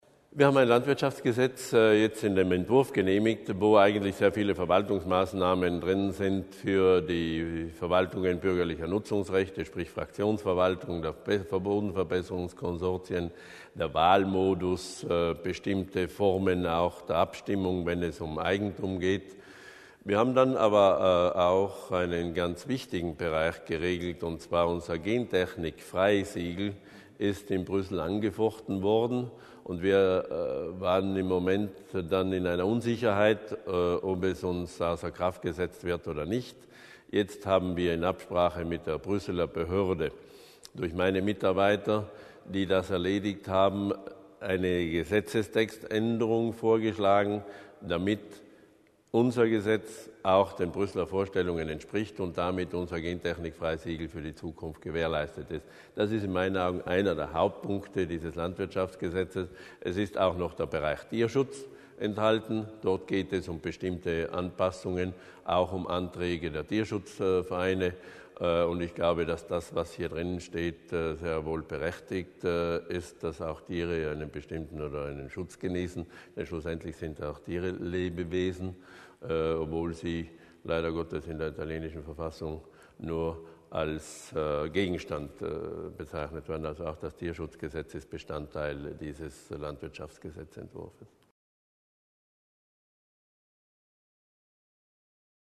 Landeshauptmannstellvertreter Berger über die Zukunft der Landwirtschaft